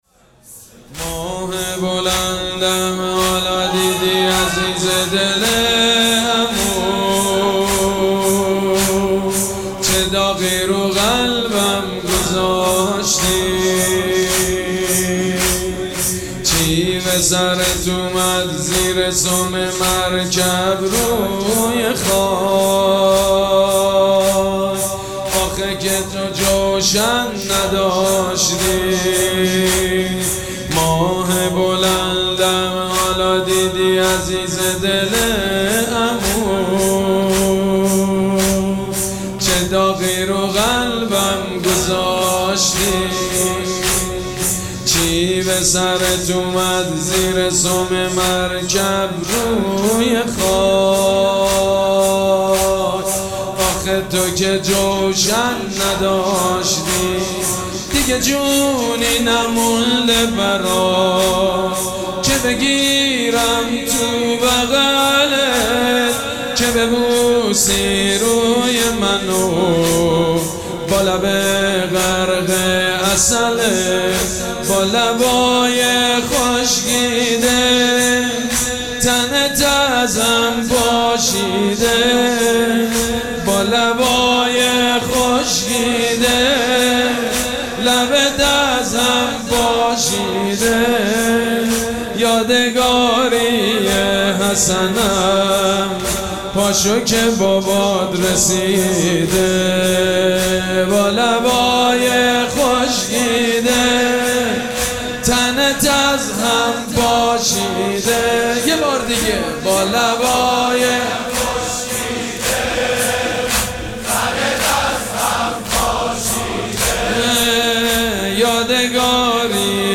مراسم عزاداری شب ششم محرم الحرام ۱۴۴۷ سه‌شنبه ۱۰تیر۱۴۰۴ | ۵ محرم‌الحرام ۱۴۴۷ هیئت ریحانه الحسین سلام الله علیها
سبک اثــر زمینه مداح حاج سید مجید بنی فاطمه